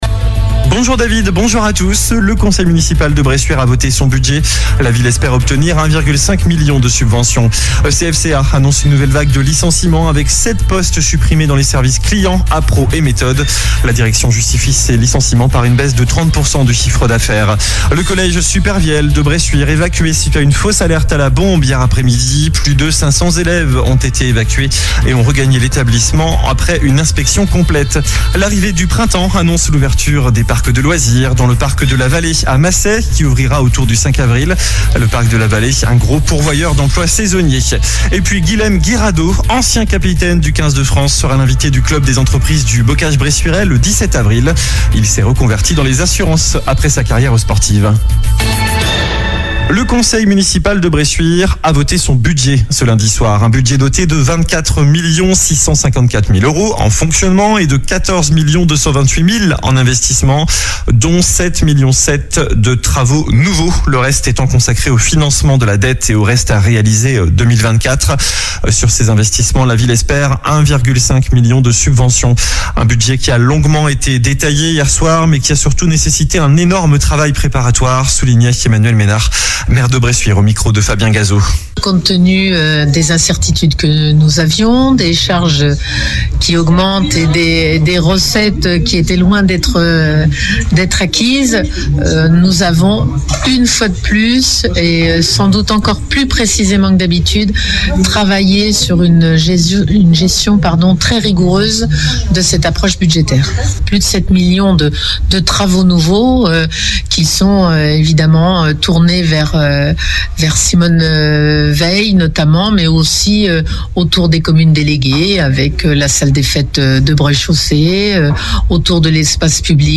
Journal du mardi 25 mars (midi)